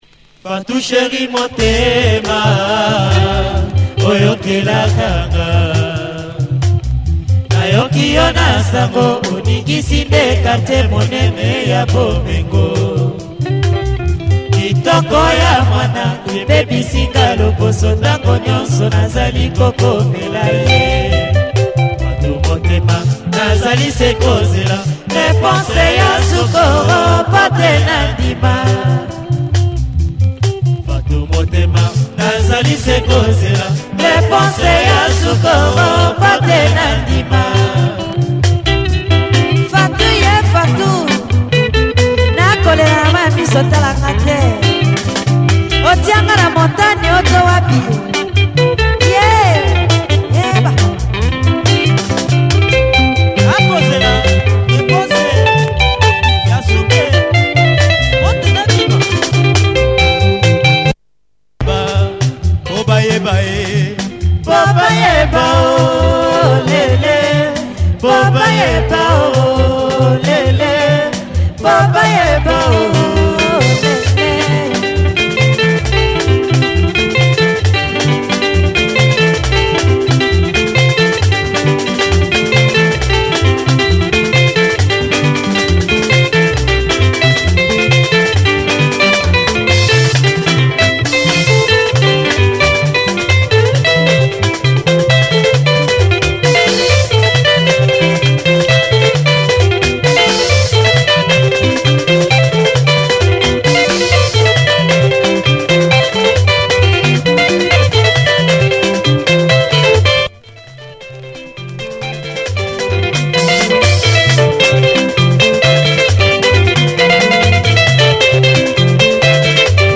Quality lingala from this Congolese group